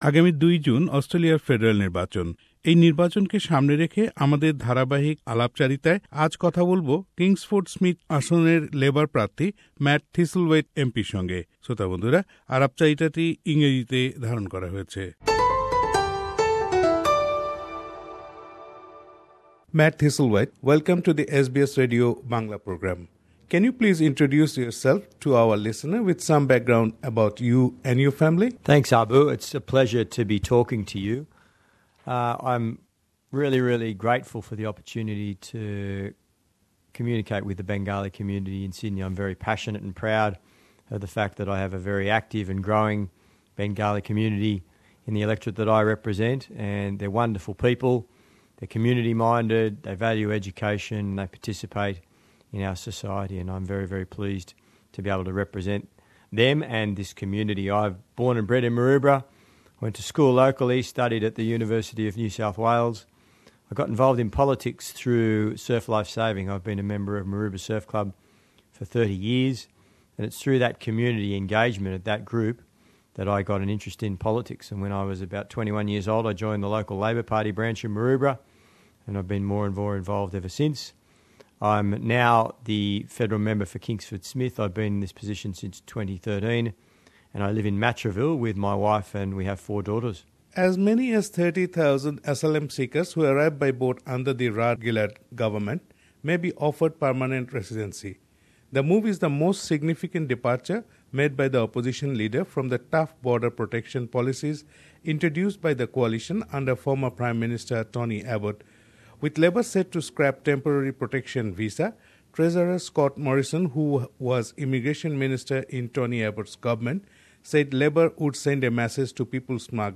Election 2016:Interview with Matt Thistle Waite MP